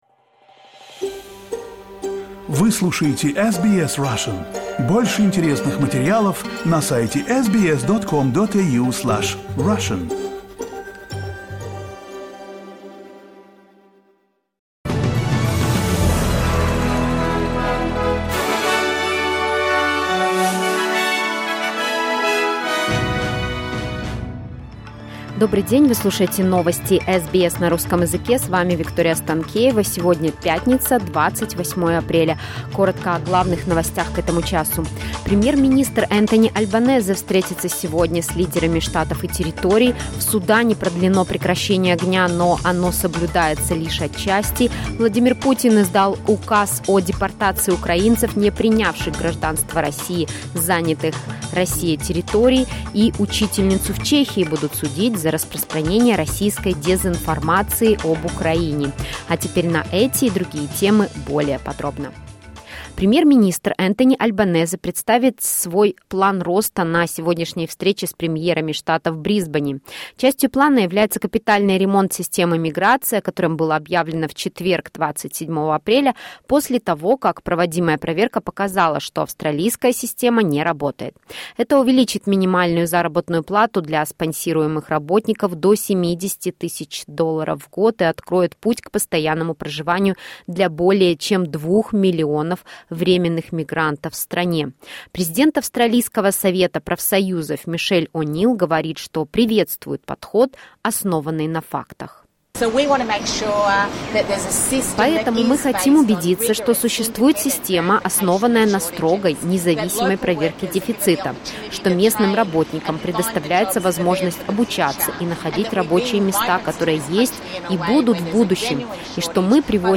SBS news in Russian — 28.04.2023